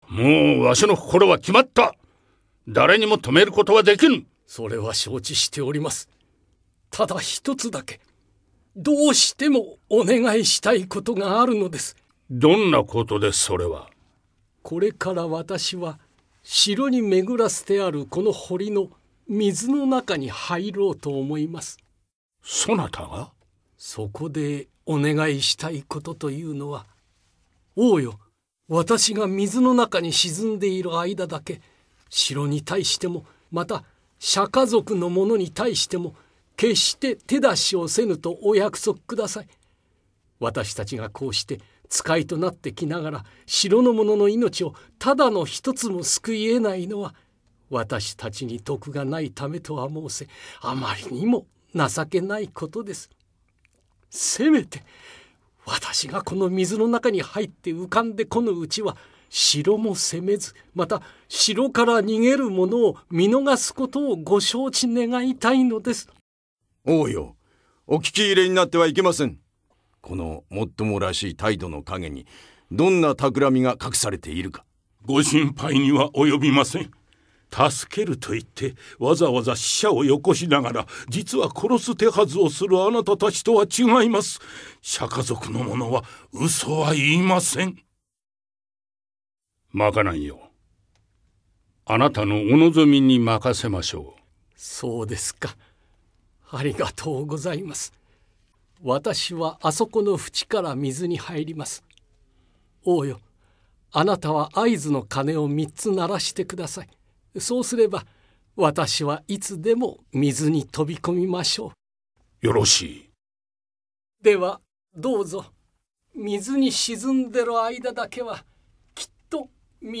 そんな時、一族の最長老である摩訶南尊者は、ある決意を胸に、流離王の元へ最後の交渉に赴くのだが…。今なお世界のあちこちで人々を戦争へと駆り立てる、それぞれの「正義」の姿を見事に描き出した声の歴史劇。
「イスタンブール」「プリテンド」など多くのヒットナンバーや「奥さまは魔女」の吹替えでもおなじみの美声は今も健在だ。半世紀を芸能の世界で生きてきた稀代の名優二人が、まさに手に汗握るセリフの応酬をたっぷり聞かせてくれる。
（53分・ステレオ）
流離王…日下武史
摩訶南尊者…柳澤愼一